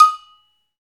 PRC XAGOGO03.wav